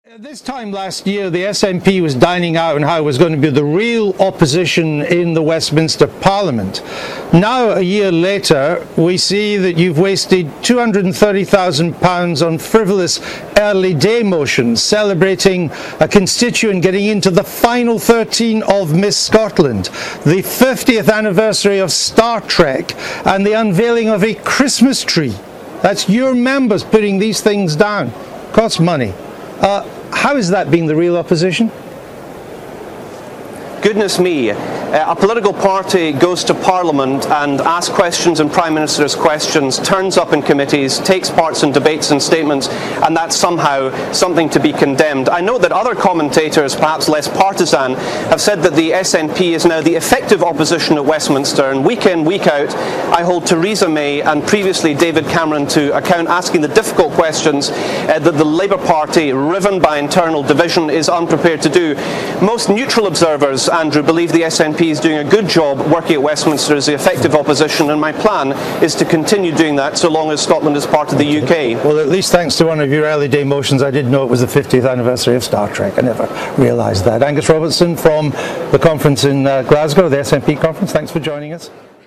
Deputy party leader Angus Robertson was furious when questioned on the motions by the BBC's Andrew Neil.